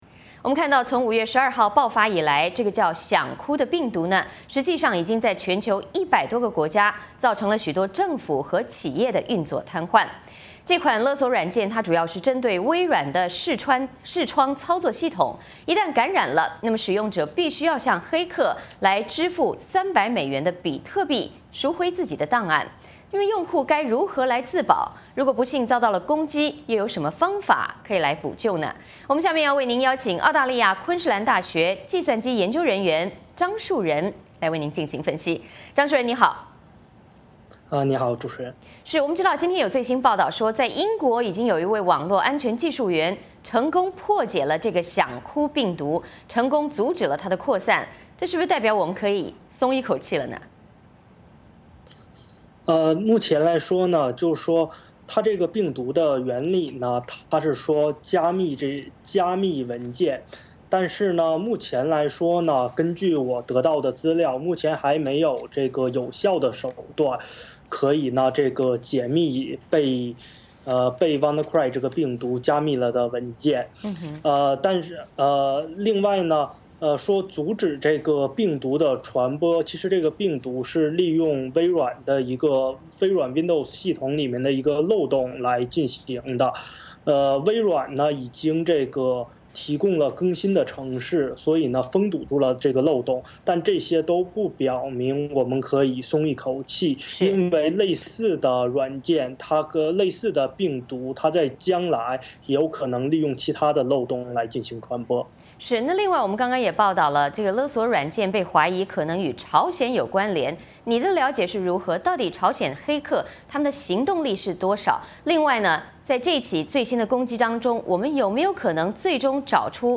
华盛顿 —